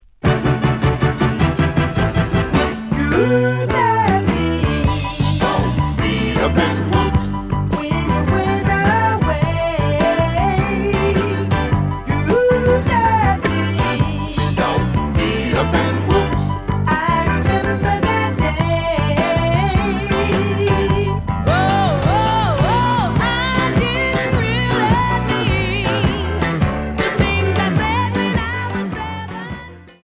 (Mono)
試聴は実際のレコードから録音しています。
●Genre: Funk, 70's Funk